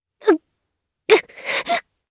Chrisha-Vox_Dead_jp.wav